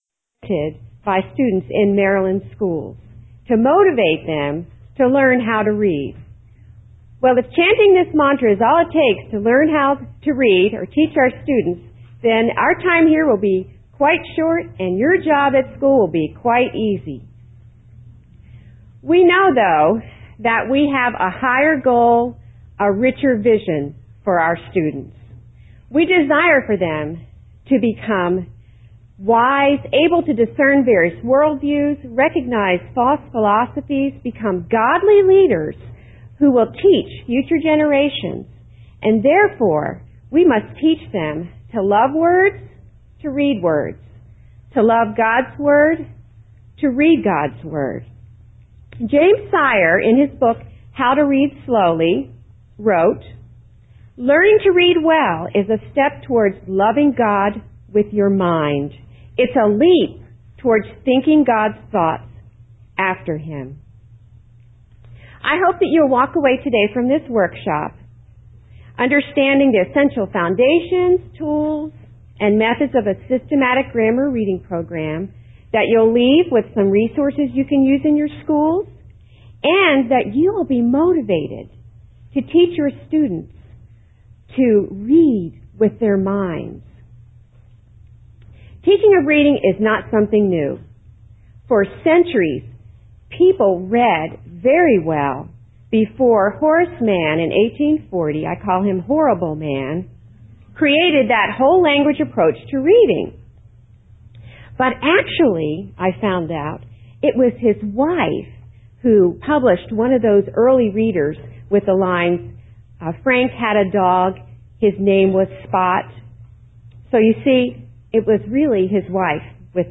2005 Workshop Talk, 0:49:46, K-6, Literature
Dec 19, 2018 | Conference Talks, K-6, Library, Literature, Workshop Talk | 0 comments